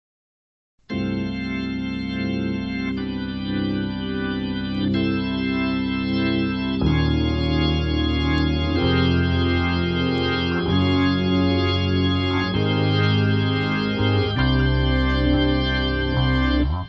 Hammond pur